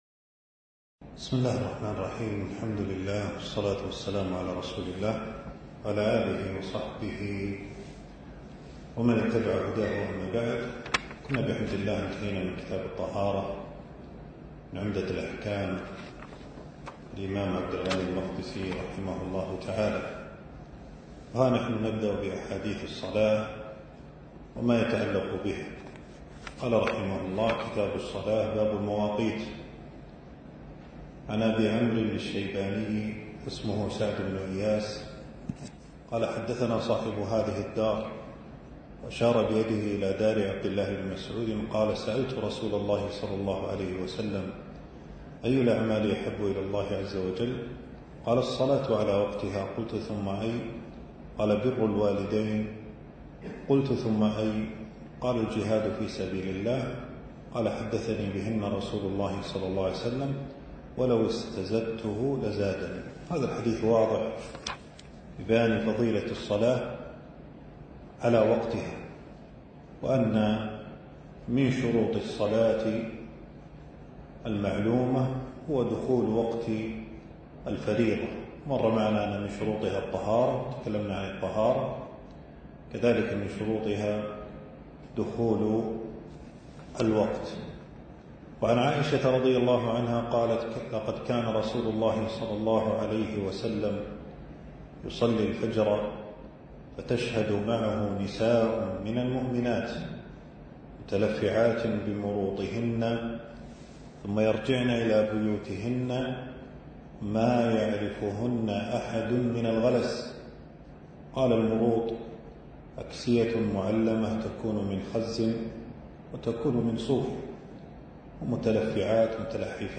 المكان: درس ألقاه في 3 جمادى الثاني 1447هـ في مبنى التدريب بوزارة الشؤون الإسلامية.